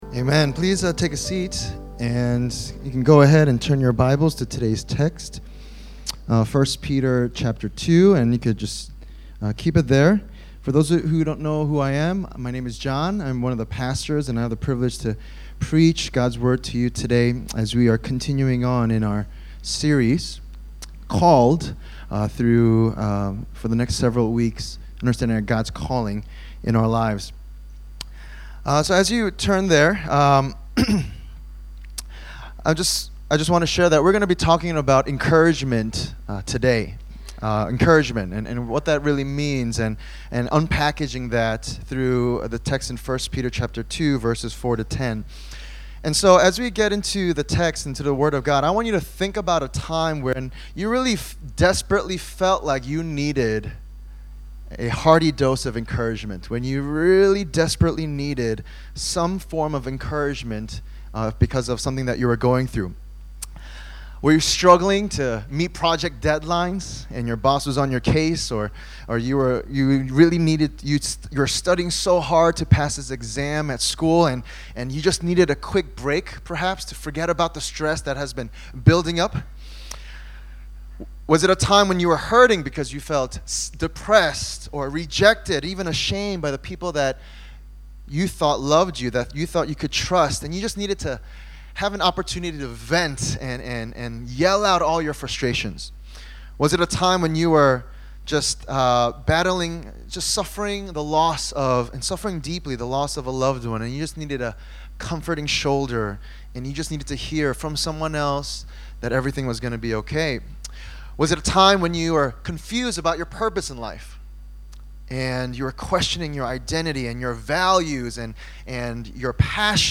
In this sermon series, we’ll be looking through Apostle Peter’s First Letter to different churches in the first century amidst a time of great suffering and persecution, and we’ll see different aspects of what God calls us to as disciples of Jesus Christ: 1: Experiencing Salvation 2: Growing Up Into Salvation 3: Knowing Who We Are Together 4: Living as Servants 5: Living as Wives & Husbands 6: Suffering for Doing Good 7: Glorifying God in Everything 8: Suffering as a Christian 9: Standing Firm as the Church